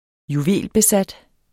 Udtale [ -beˌsad ]